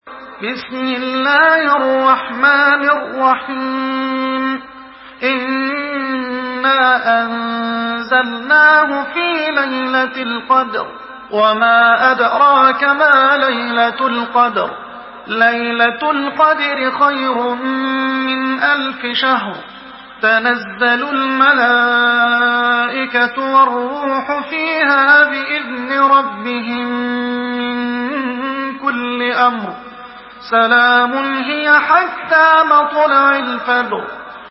Murattal Hafs An Asim